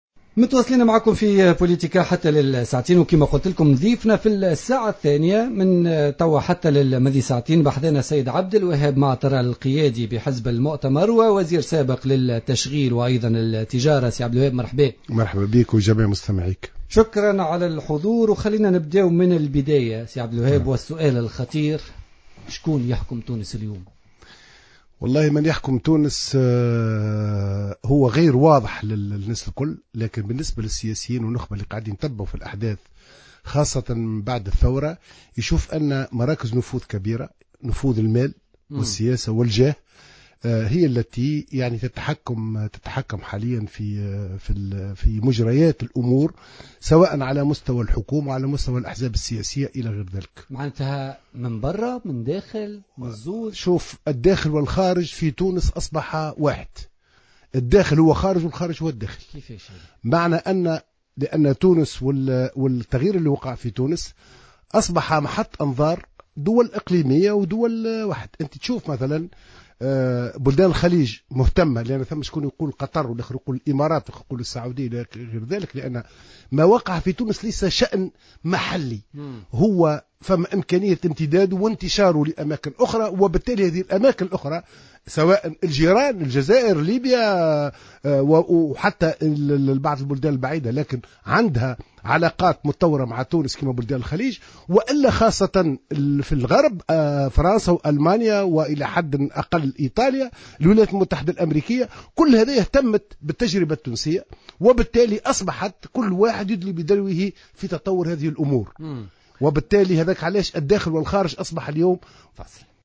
وأوضح في مقابلة على "الجوهرة أف أم" في برنامج "بوليتيكا" اليوم الثلاثاء أنه من غير الواضح لعموم الناس من يحكم في تونس لكن الأمر واضح بالنسبة للسياسيين والنخبة المتابعة للأحداث، بحسب ترجيحه.